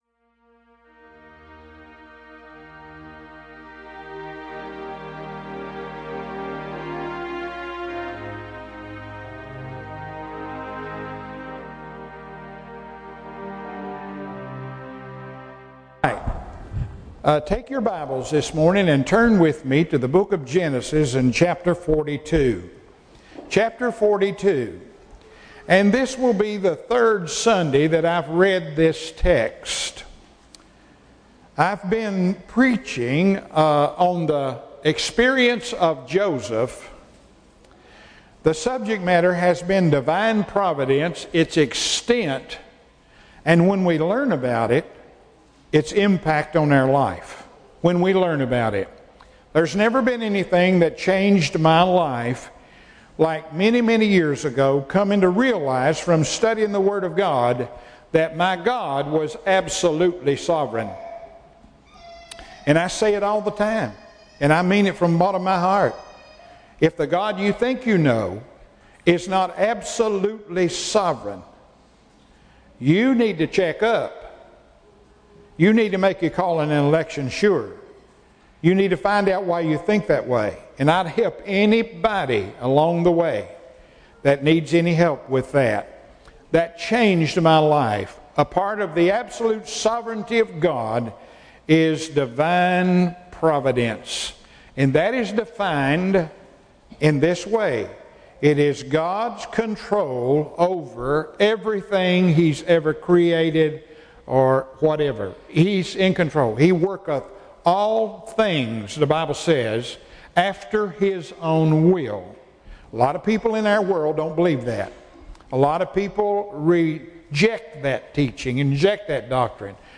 June 6, 2021 Divine Providence (Continued) Passage: Genesis 42:17-24 Service Type: Morning Service Message From Emmanuel is a weekly audio ministry of Emmanuel Baptist Church in Irvine, KY.